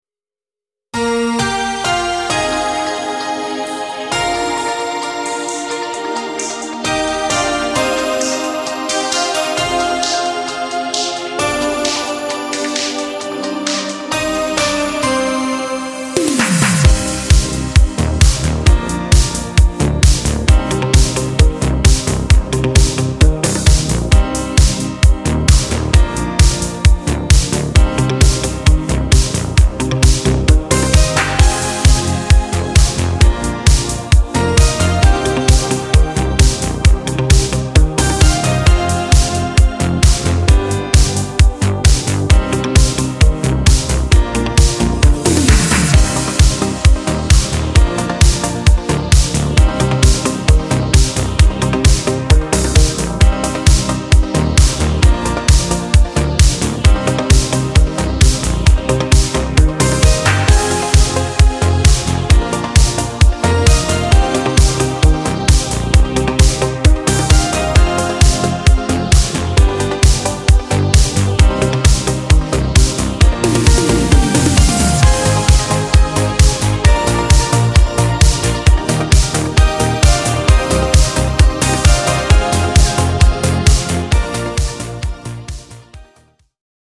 podkład dla wokalistów
Disco Polo